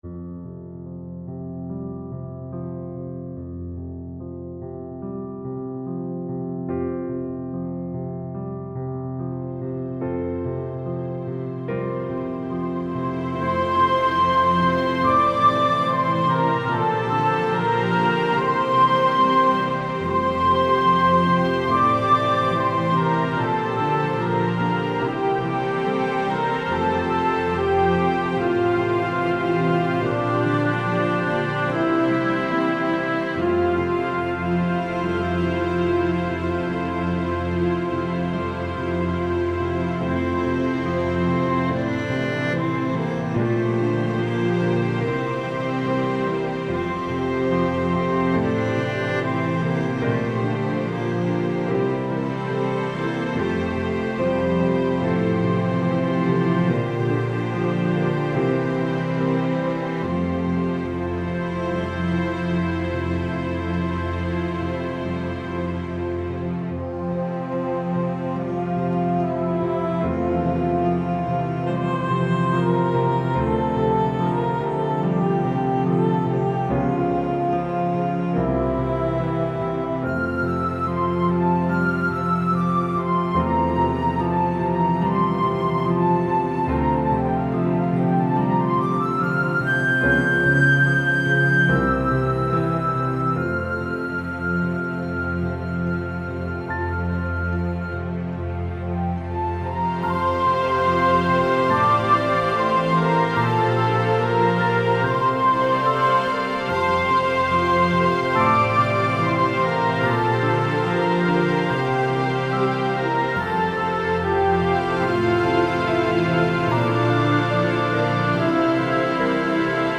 However, the mix adds a slightly greater sense of depth.